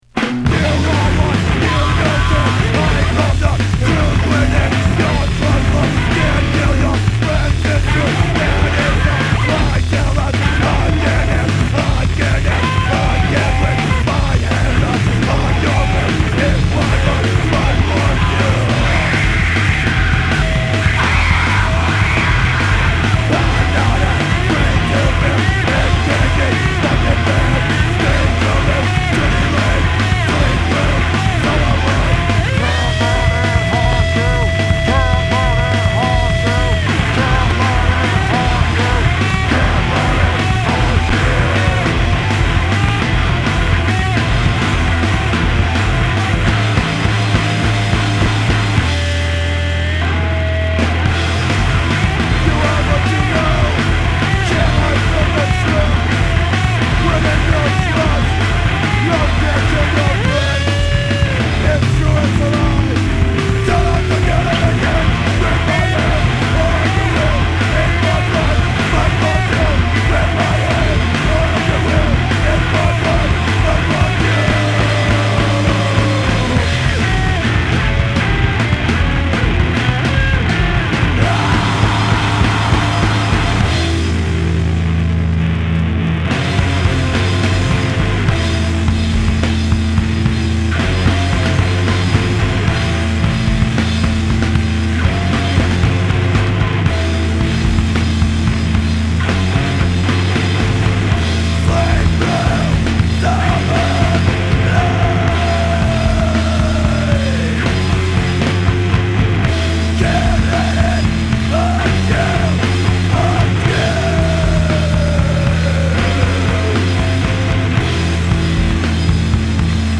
Guitar
Vocals
Bass
Drums
Hardcore